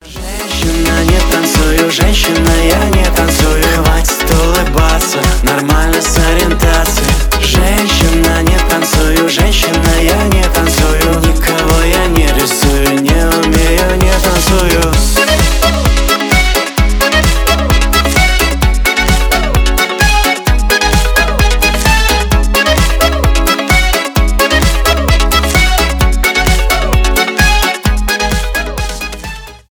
веселые , танцевальные
поп